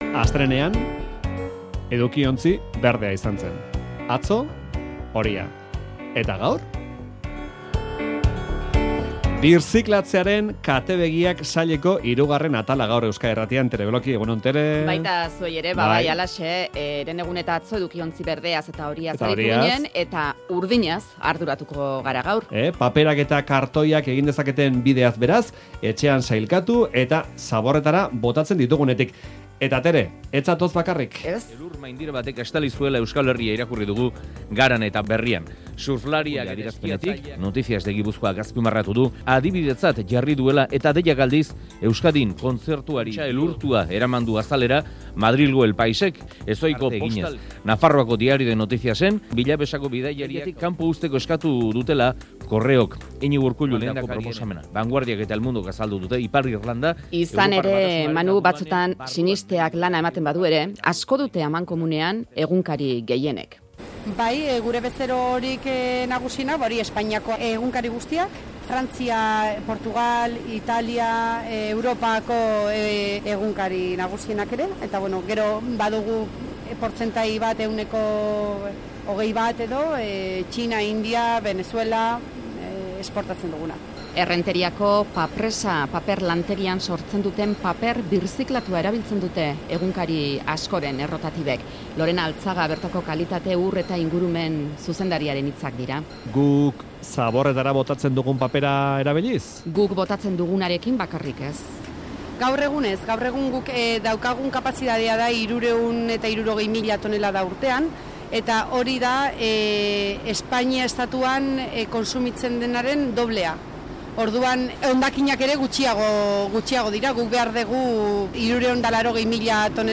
Audioa: 'Birziklatzearen katebegiak' erreportaje sortaren hirugarren atalean, paperak eta kartoiak birziklatzeko egiten duten bidea ikusi dugu.